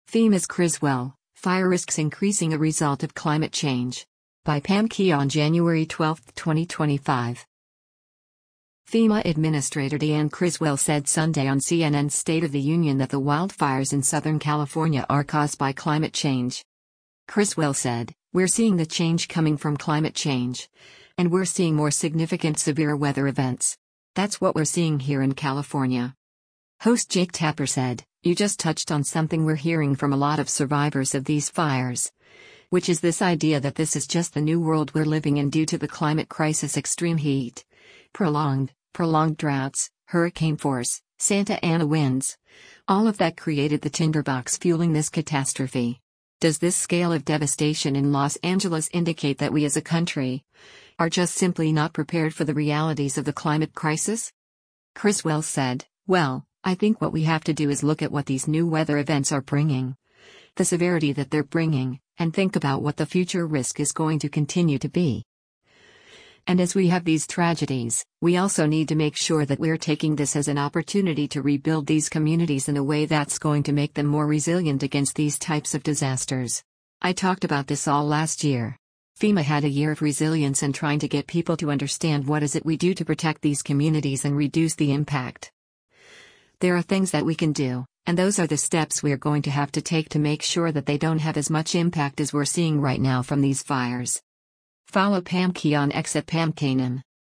FEMA administrator Deanne Criswell said Sunday on CNN’s “State of the Union” that the wildfires in Southern California are caused by climate change.